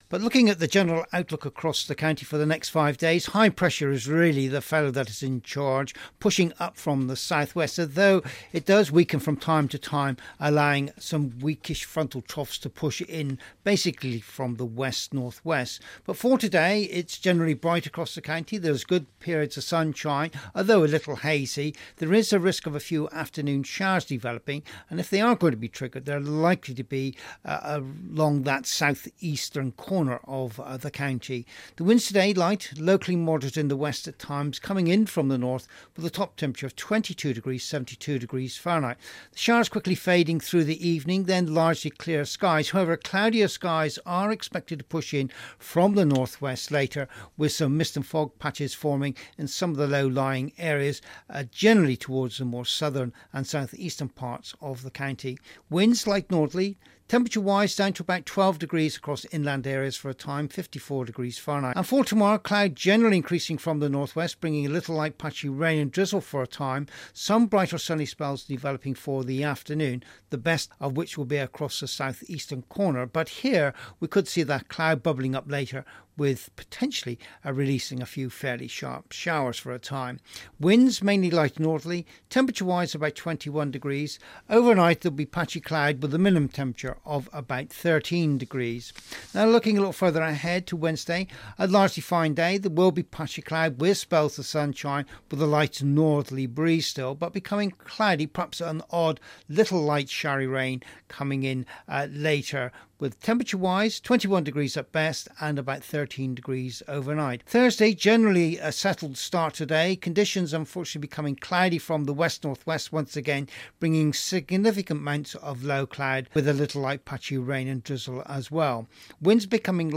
5 Day weather forecast for Cornwall and the Isle of Scilly